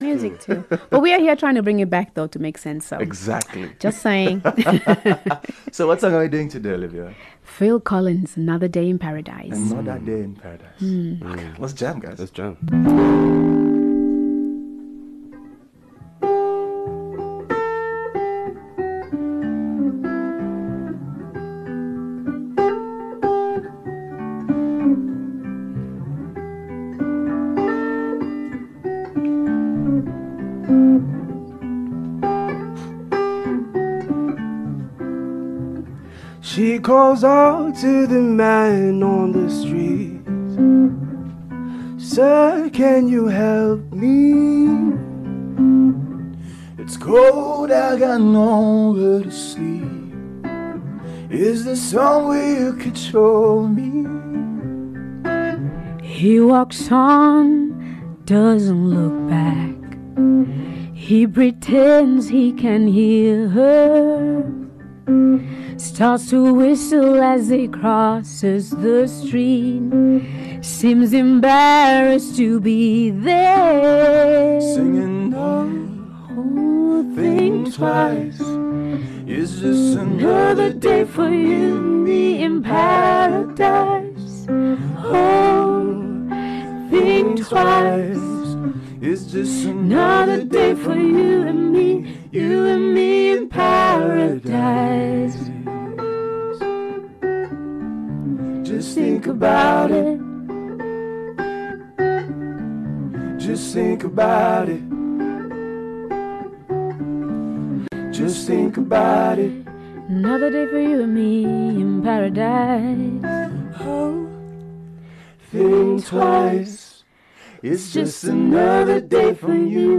joins us in studio for a good Friday live jam.